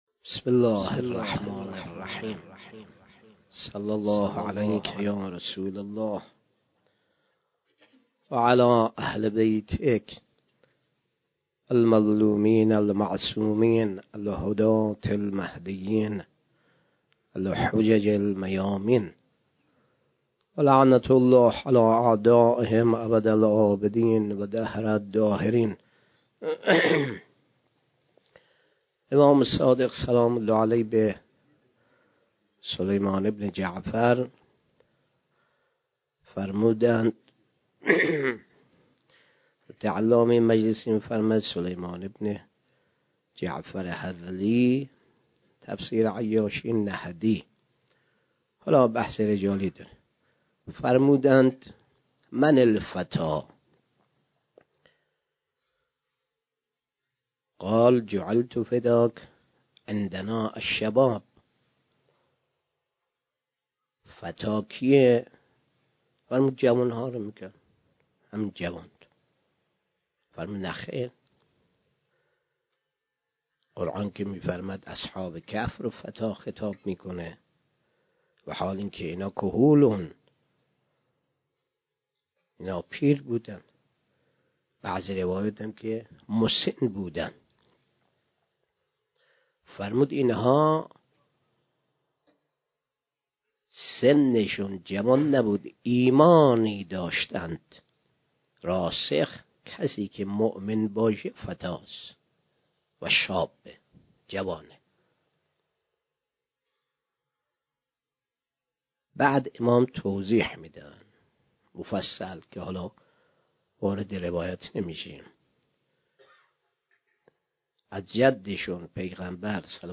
شب هشتم محرم 97 - غمخانه بی بی شهربانو - سخنرانی